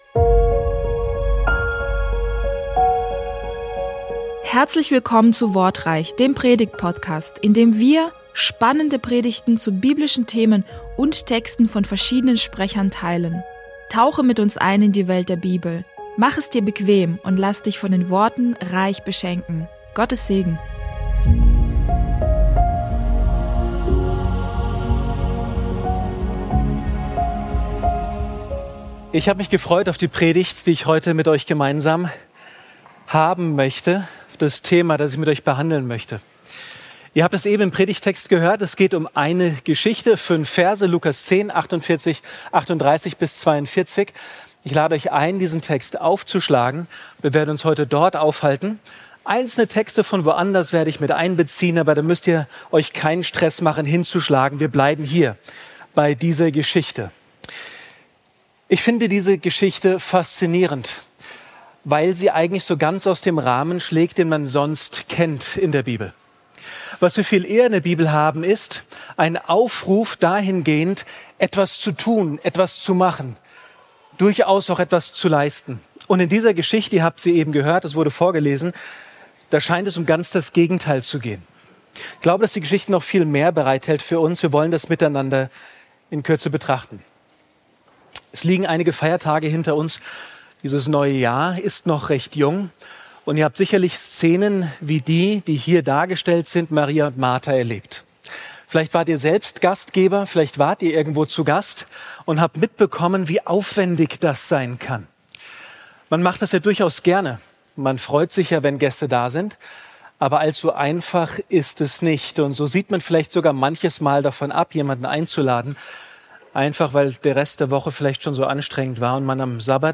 Hier hörst du Predigten aus Bogenhofen von unterschiedlichen Predigern, die dich näher zu Gott bringen und deinen Glauben festigen.